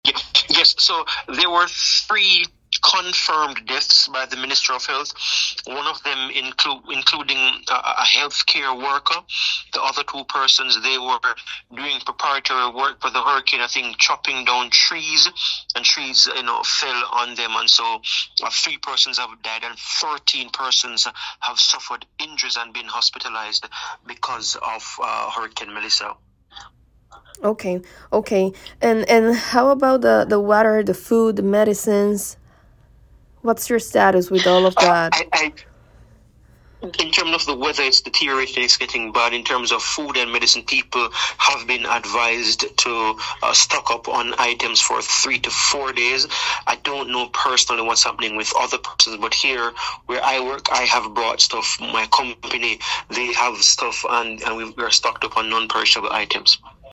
Esto es solo el principio“, aseguró mediante llamada telefónica.